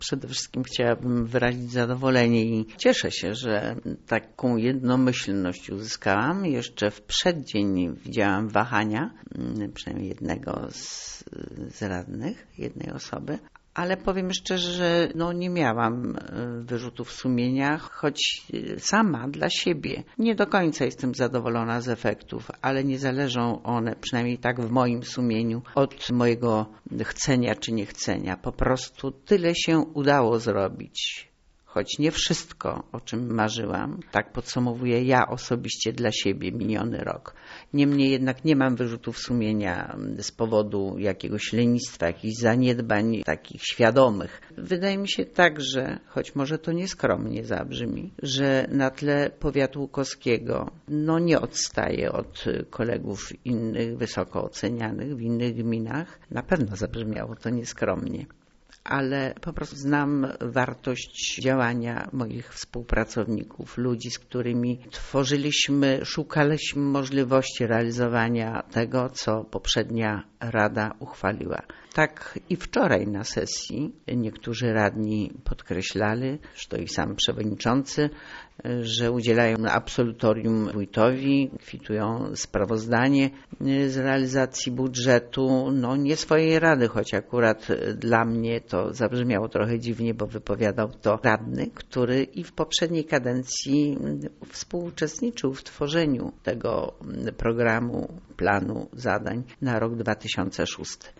tutaj i posłuchaj wypowiedzi wójta